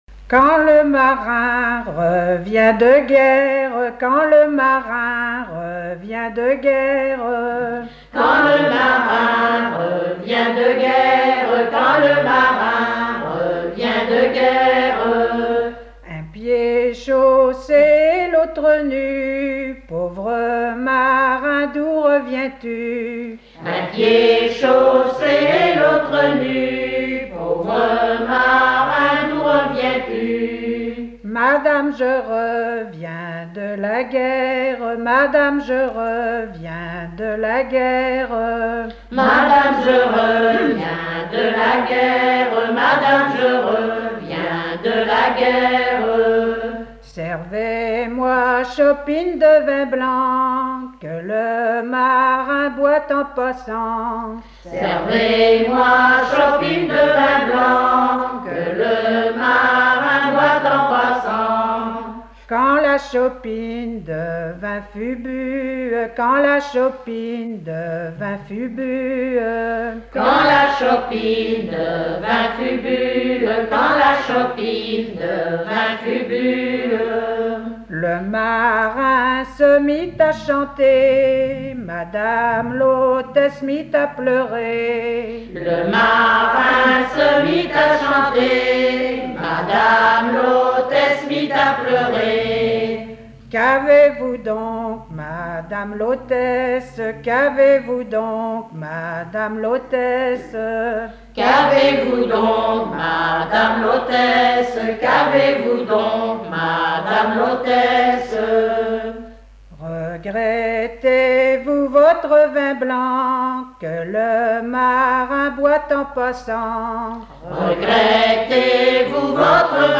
Mémoires et Patrimoines vivants - RaddO est une base de données d'archives iconographiques et sonores.
repertoire de chansons traditionnelles
Pièce musicale inédite